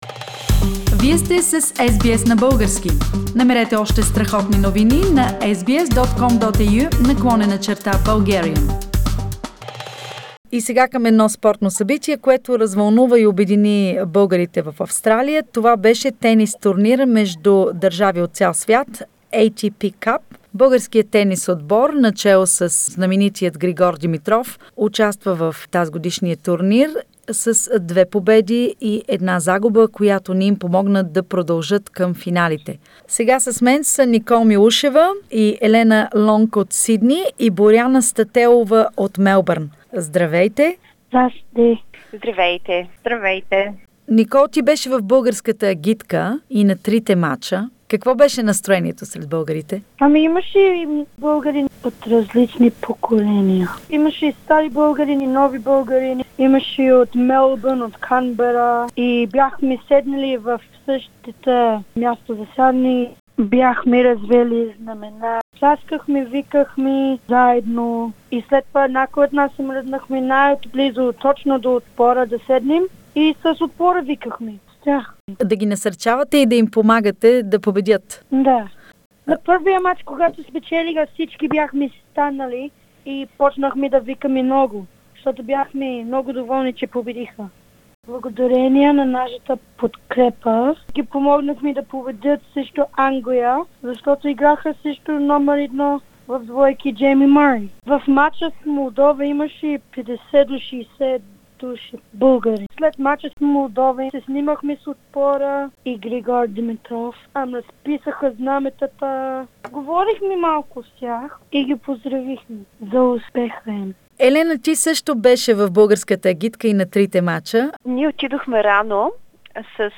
The Bulgarian team did their best for the three matches played in Sydney for the ATP CUP - what the Bulgarian fans thought of the presentation of the Bulgarian team led by the famous Grigor Dimitrov. Interview